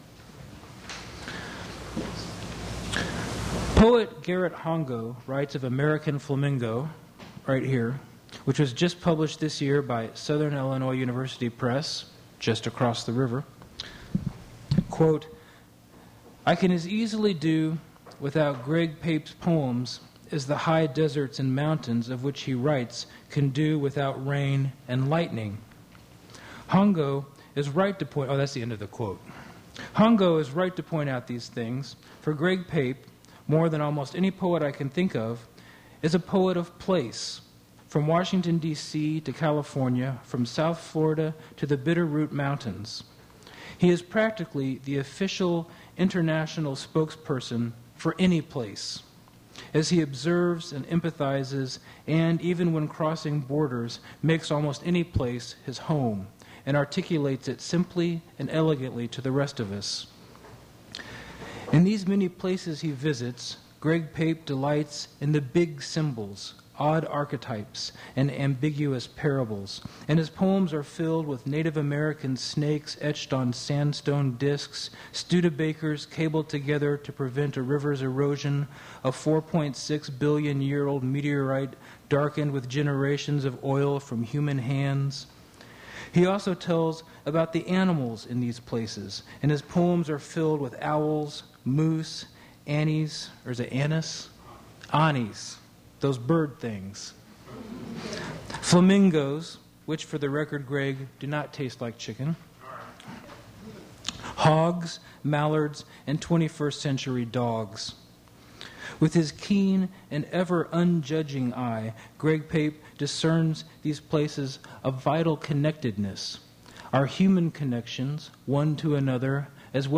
generated from original audio cassette
Cut irrelevant portion of intro; cut chatter between speakers at the end of this recording